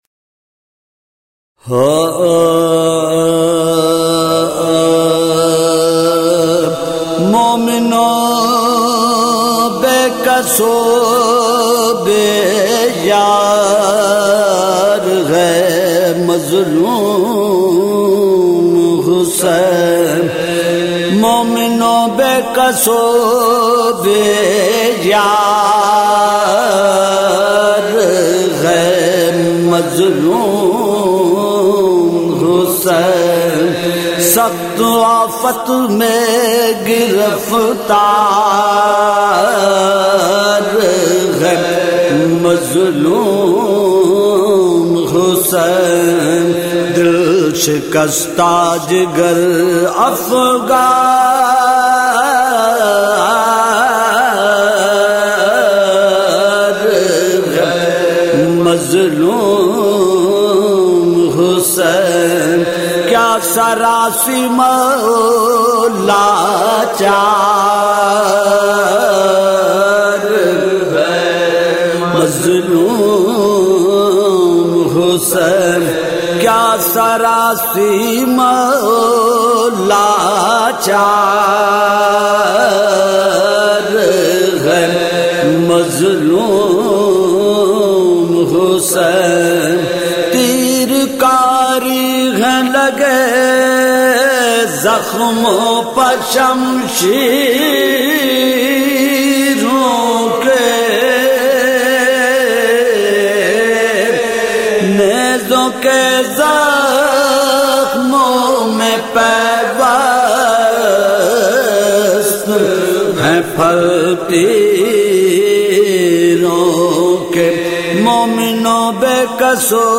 سوزوسلام اورمرثیے